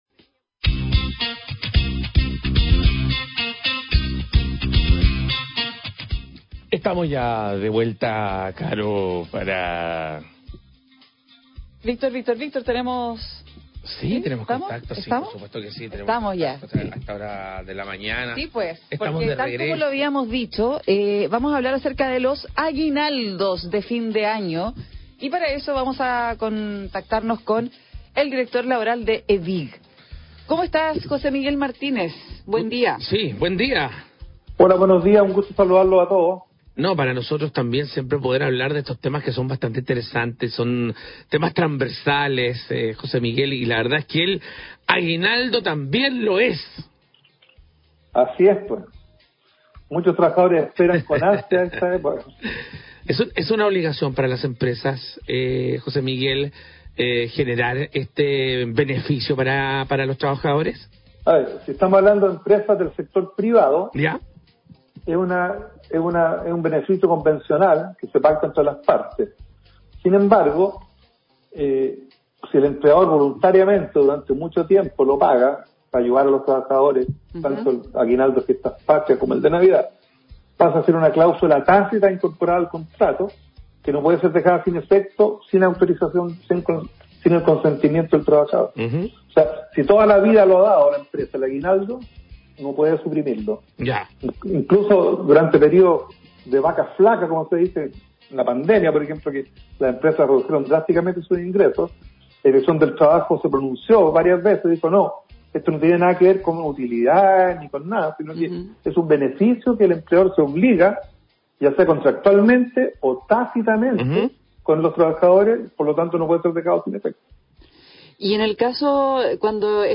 Entrevista Radio UFRO sobre los Aguinaldos de Navidad